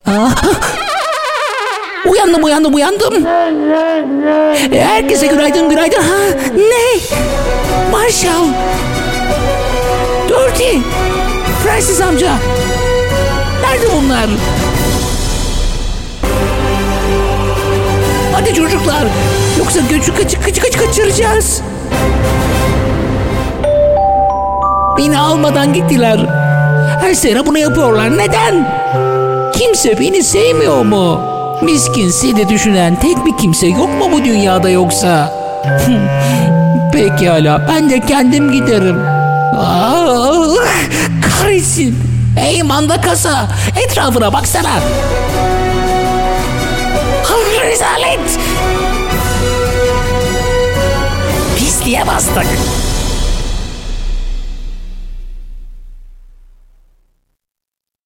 Turkish Voiceover Artist.
Sprechprobe: Sonstiges (Muttersprache):